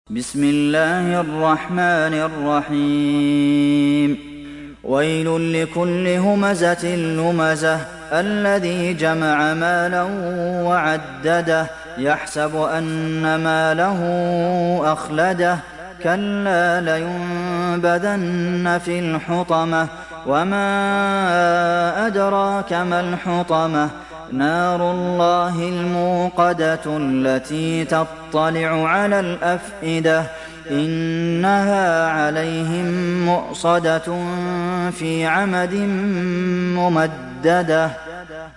دانلود سوره الهمزه mp3 عبد المحسن القاسم روایت حفص از عاصم, قرآن را دانلود کنید و گوش کن mp3 ، لینک مستقیم کامل